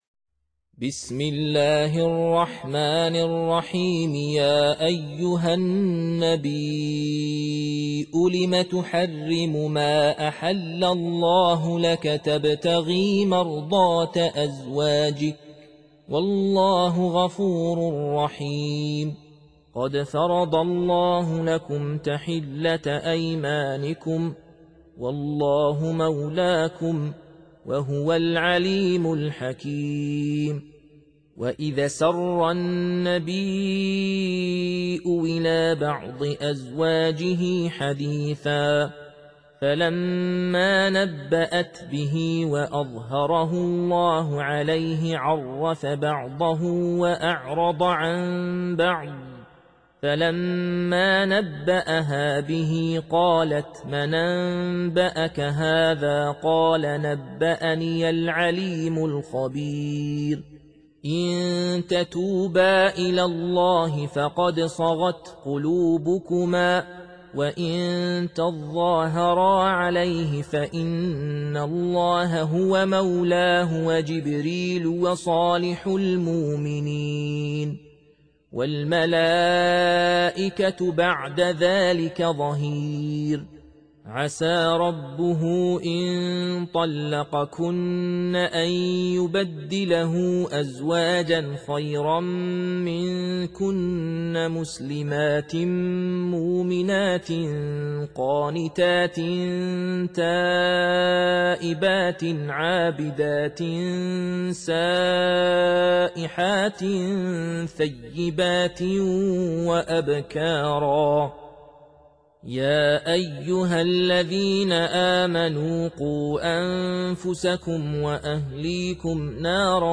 Surah Repeating تكرار السورة Download Surah حمّل السورة Reciting Murattalah Audio for 66. Surah At-Tahr�m سورة التحريم N.B *Surah Includes Al-Basmalah Reciters Sequents تتابع التلاوات Reciters Repeats تكرار التلاوات